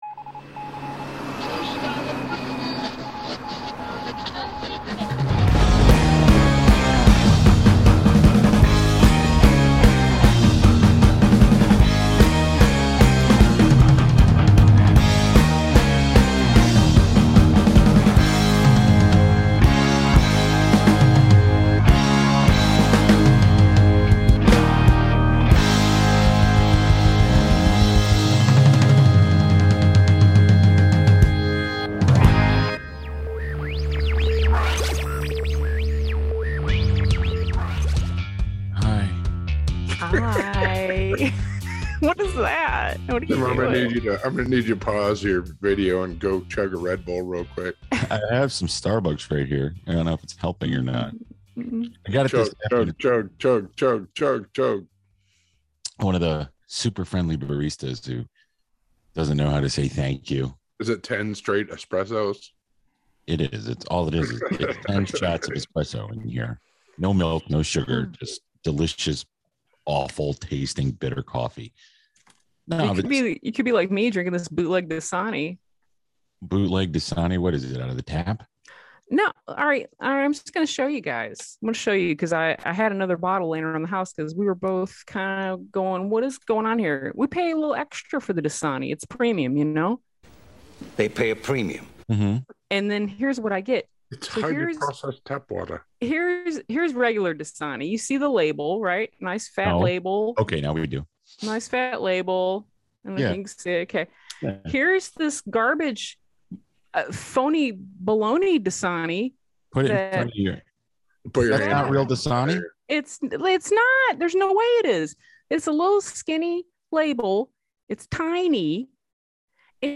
We are all here this weekend and please pardon the zoom audio it’s not perfect, but its what we are working with at the moment.